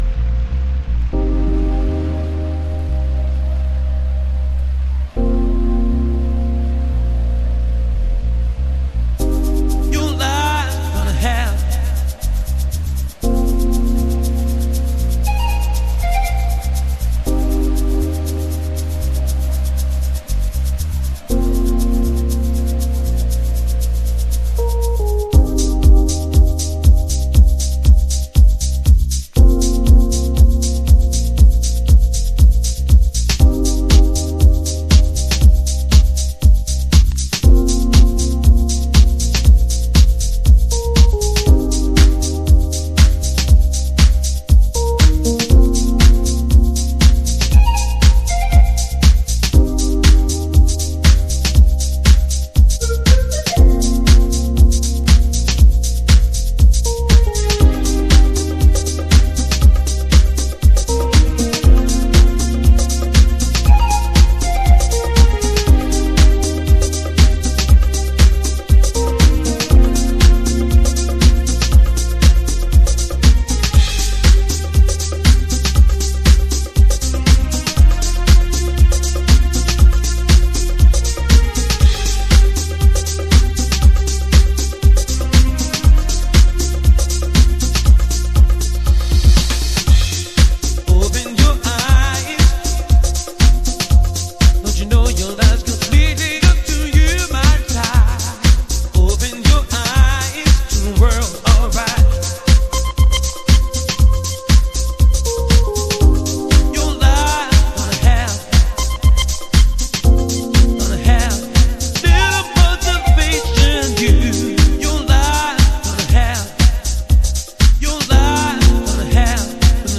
暖かいアコースティックと高揚ヴォーカルのハルモニア。
House / Techno